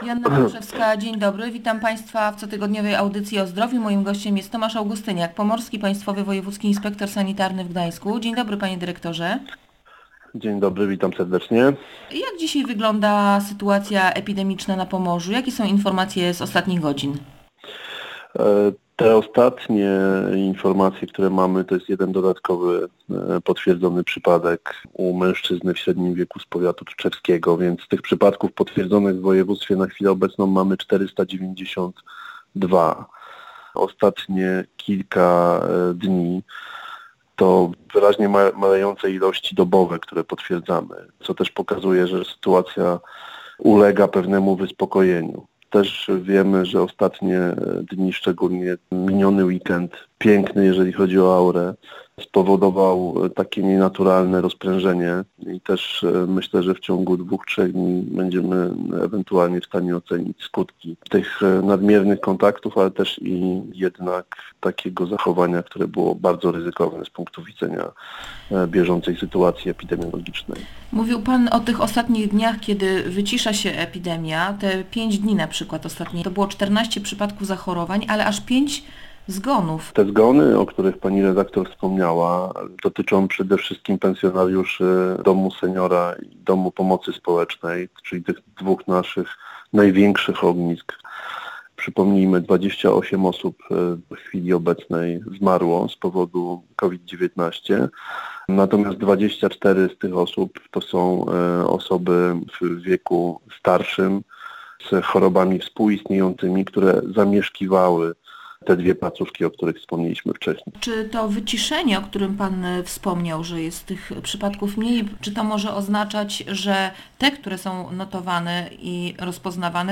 W audycji mowa była o planowym uruchomieniu od przyszłego tygodnia usług kosmetyczno-fryzjerskich i branży gastronomicznej. Szef inspekcji mówił, jakich zasad powinno się przestrzegać w tych placówkach oraz, jak klienci powinni się przygotować do skorzystania z usług.